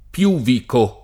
piuvicare
vai all'elenco alfabetico delle voci ingrandisci il carattere 100% rimpicciolisci il carattere stampa invia tramite posta elettronica codividi su Facebook piuvicare v.; piuvico [ p L2 viko ], -chi — arcaismo pop. per «pubblicare»